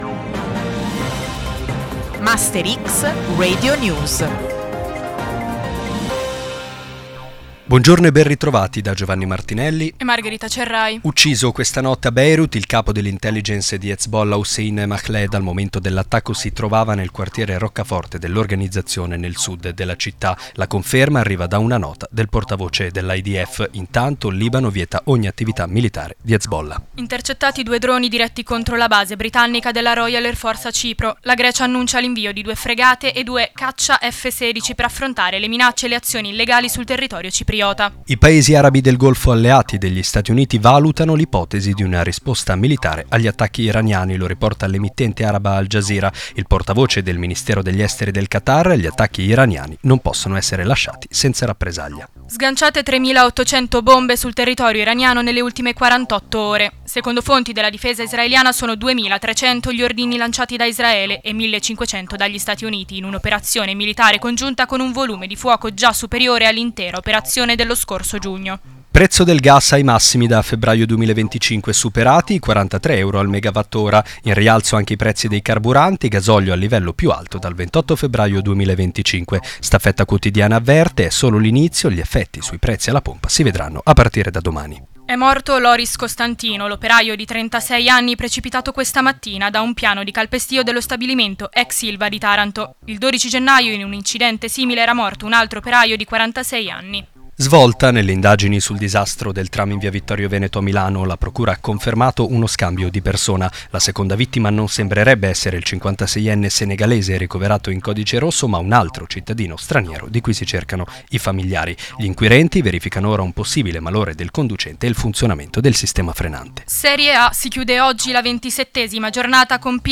Queste le ultime notizie di MasterX Radio News.